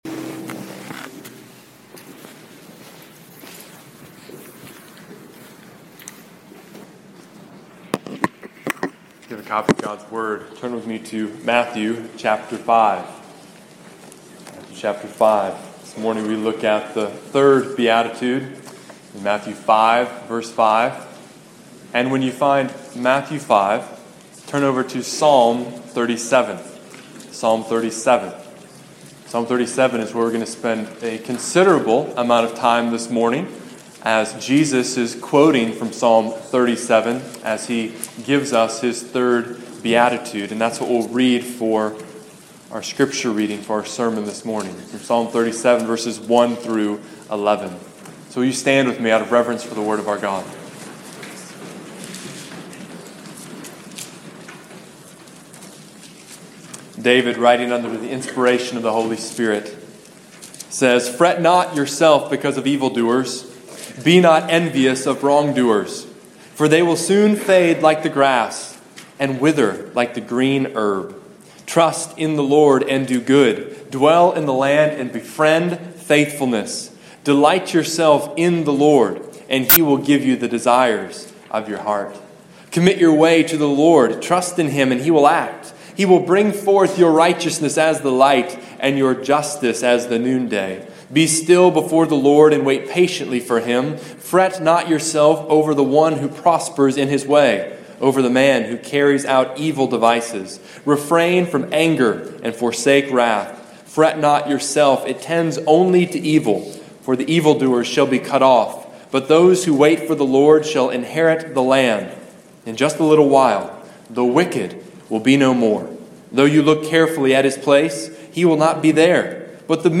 In my sermon, I spoke about three kinds of people: